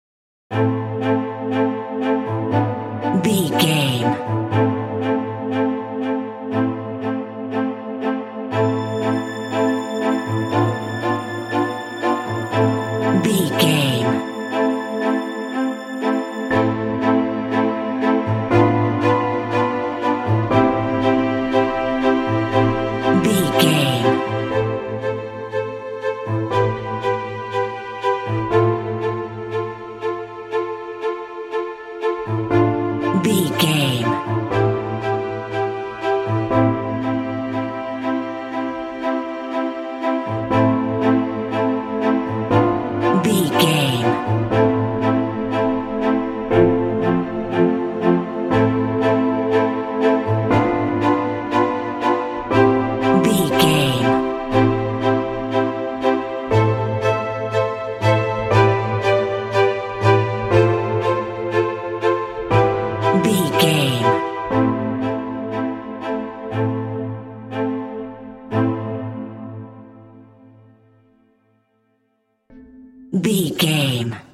Aeolian/Minor
Slow
foreboding
anxious
strings
piano
contemporary underscore